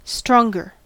Ääntäminen
Ääntäminen US Tuntematon aksentti: IPA : /ˈstɹɔŋ.ɡɚ/ IPA : /ˈstɹɑŋ.ɡɚ/ IPA : /ˈstɹɒŋ.ɡə/ Haettu sana löytyi näillä lähdekielillä: englanti Käännöksiä ei löytynyt valitulle kohdekielelle. Stronger on sanan strong komparatiivi.